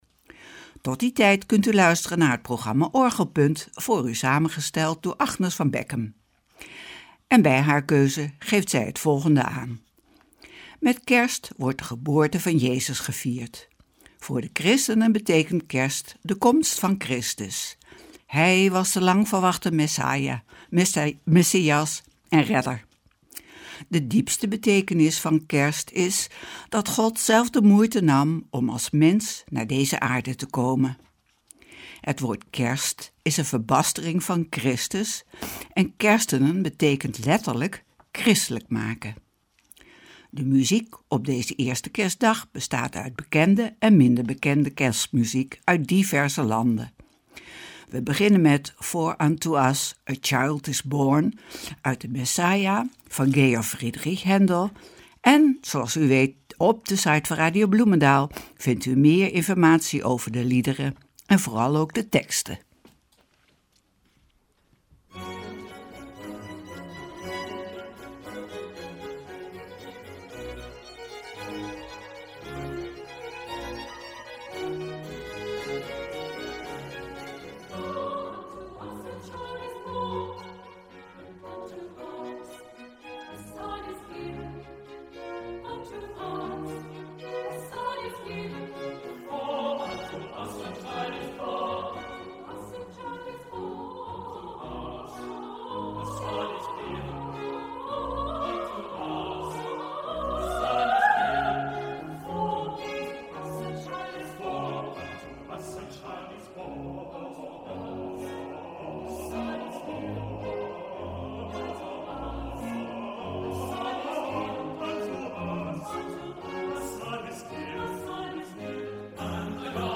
Opening van deze eerste Kerstdag met muziek, rechtstreeks vanuit onze studio.
De muziek op deze eerste kerstdag bestaat uit bekende en minder bekende kerstmuziek uit diverse landen.